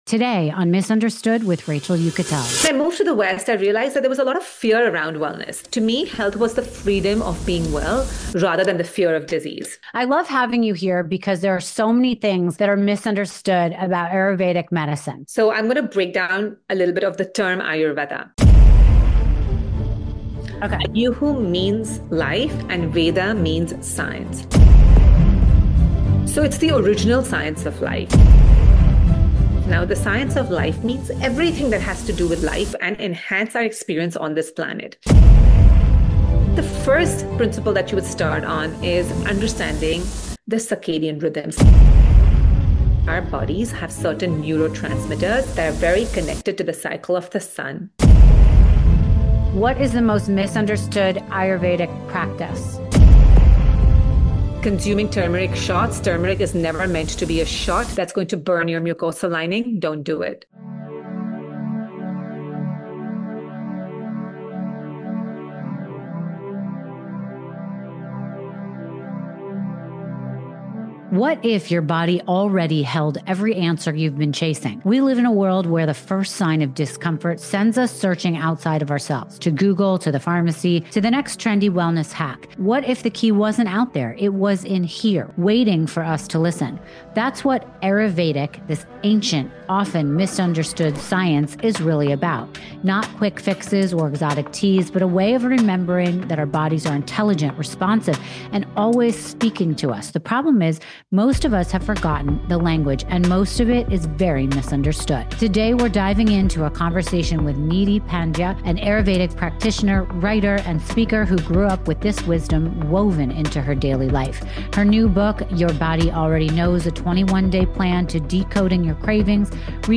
Whether you’re struggling with low energy, gut issues, or just a sense of disconnection from yourself, this conversation is an invitation to slow down, tune in, and remember that your body already knows the way.